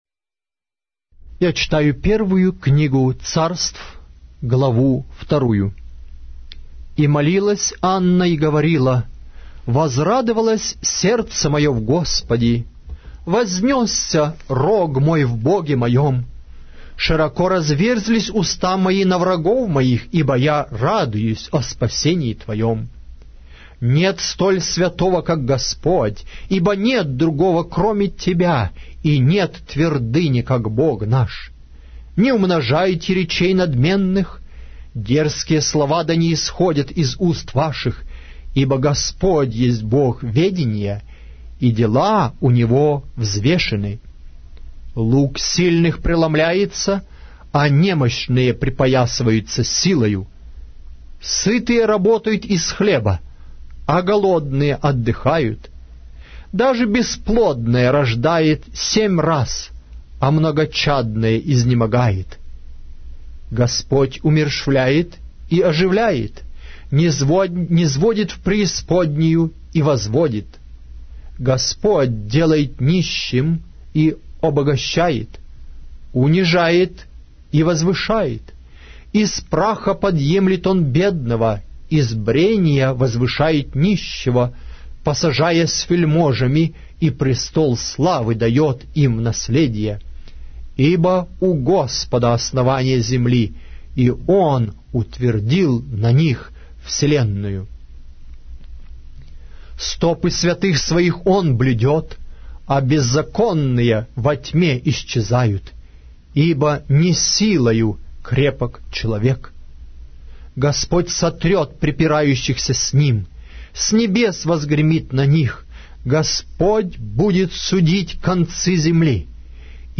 Глава русской Библии с аудио повествования - 1 Samuel, chapter 2 of the Holy Bible in Russian language